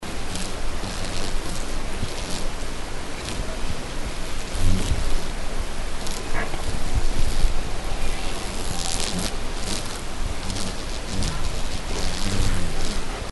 Under en timmes tid myllrade det av myror vid trappen in till huset, hälften med vingar, hälften utan.
flygmyror_96.mp3